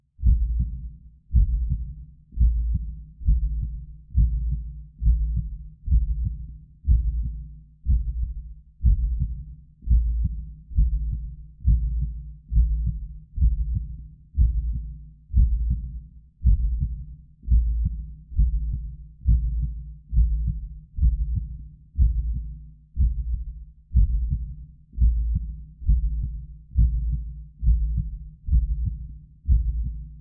Creepy Echoic Heartbeat Sound Effect Free Download
Creepy Echoic Heartbeat